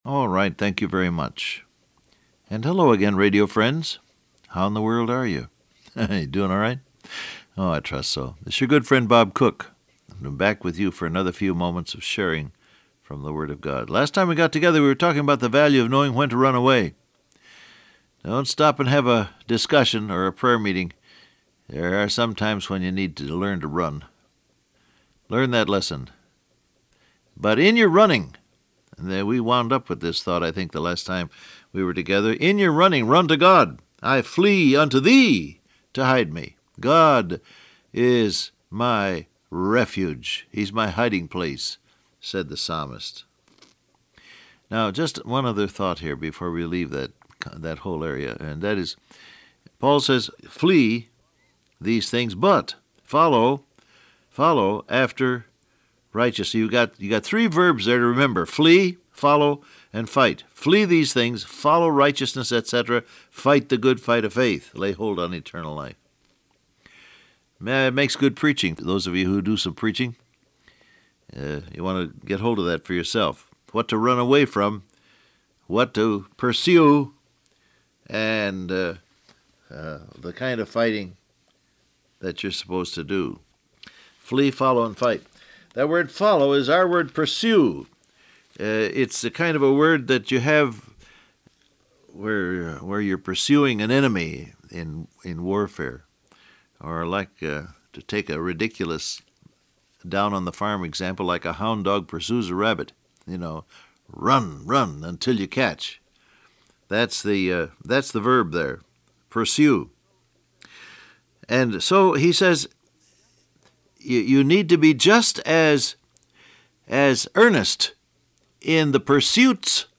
Broadcast